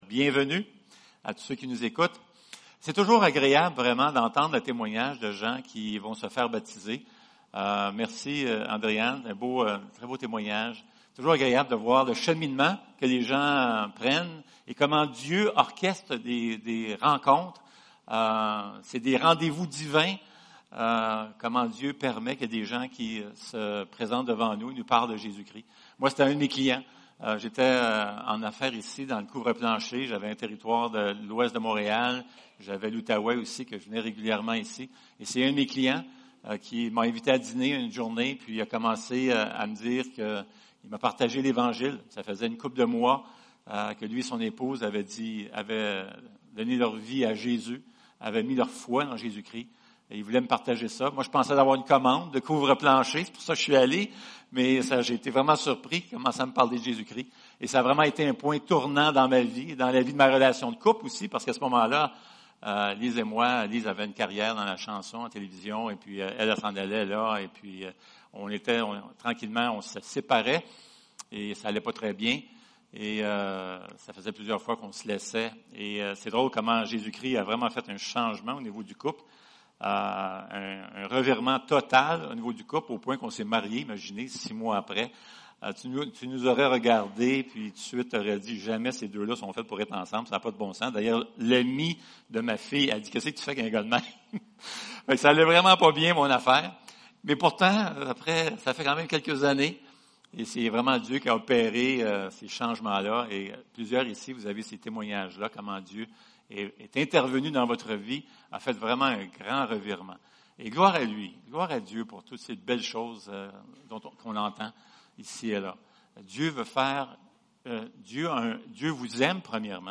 Service de baptême < église le Sentier | Jésus t'aime!